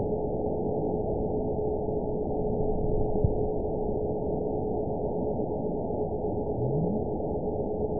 event 920509 date 03/28/24 time 12:19:47 GMT (1 year, 2 months ago) score 9.30 location TSS-AB05 detected by nrw target species NRW annotations +NRW Spectrogram: Frequency (kHz) vs. Time (s) audio not available .wav